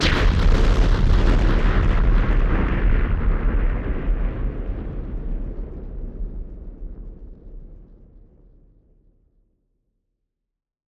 BF_SynthBomb_C-05.wav